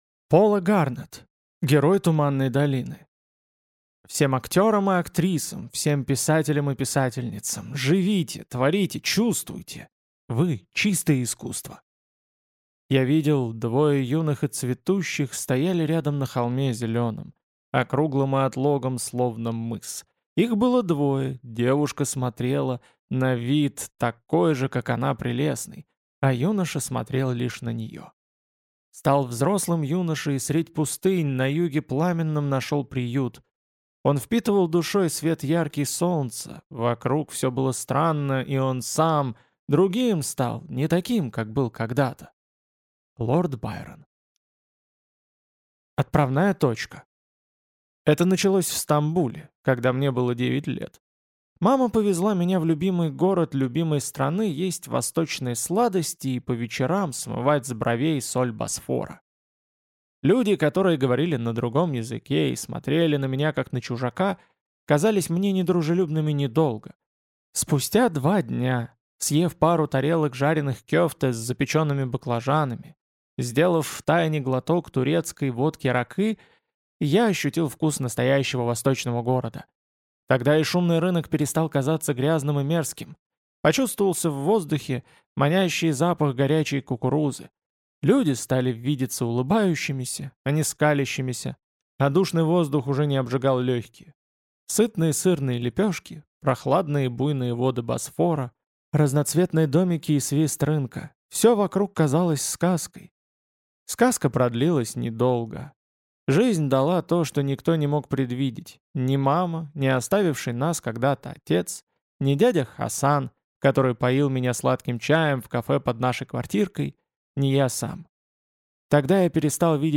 Аудиокнига Герой туманной долины | Библиотека аудиокниг